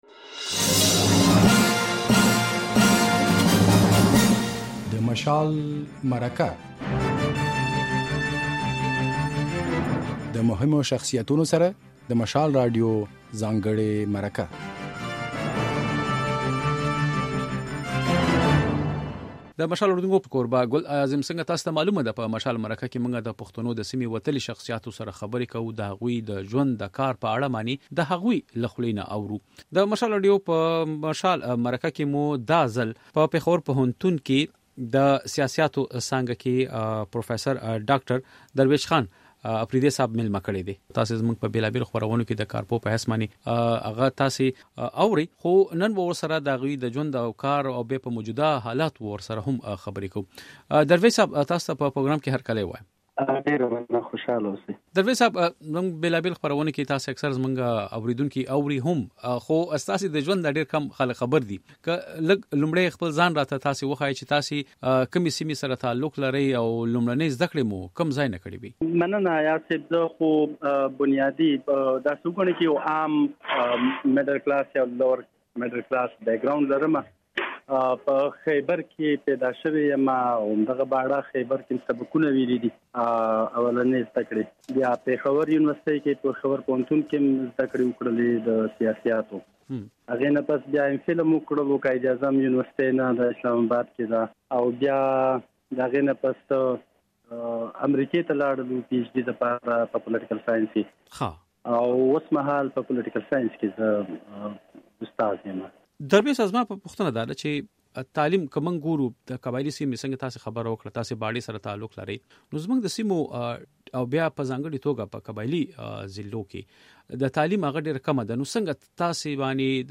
نوموړي زياته کړه چې په موجوده حالاتو کې د جمهوريت په اړه يوې بلې لوظنامې ته اړتيا ده. بشپړه مرکه واورئ.